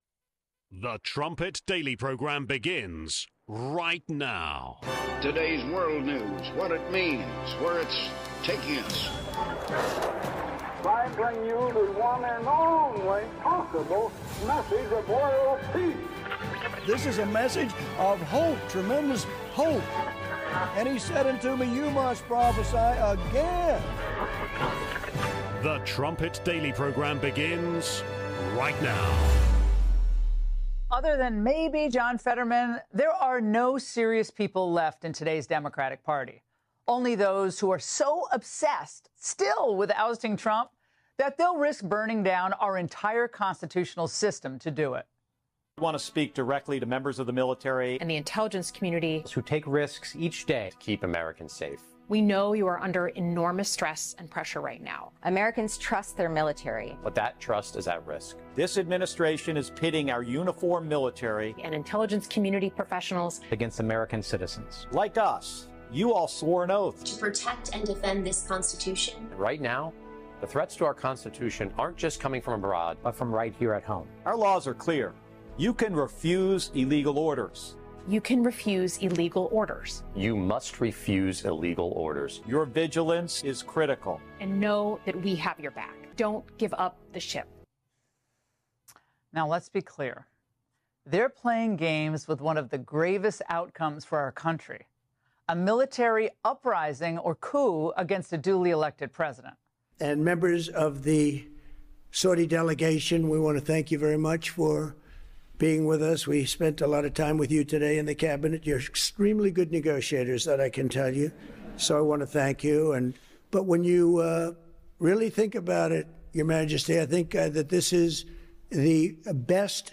Trumpet Daily Radio Show brings you a deeper understanding of the Bible and how it connects to your world and your life right now.